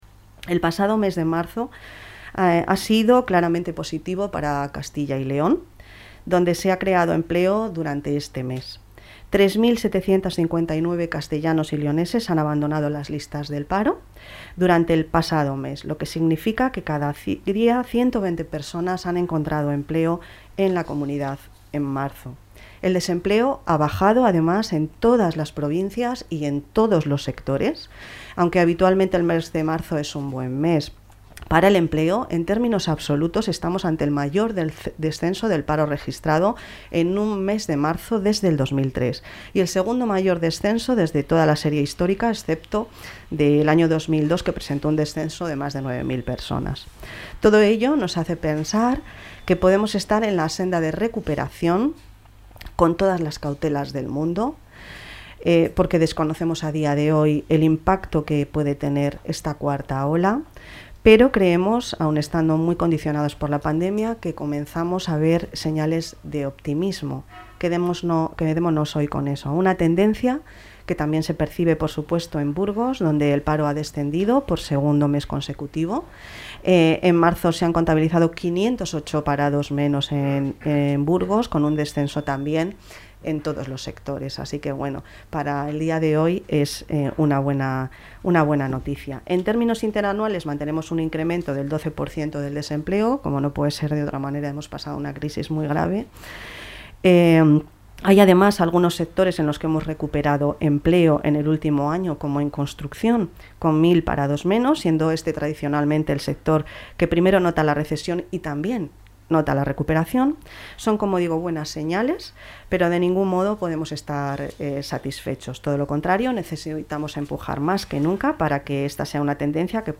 Valoración de la consejera de Empleo e Industria.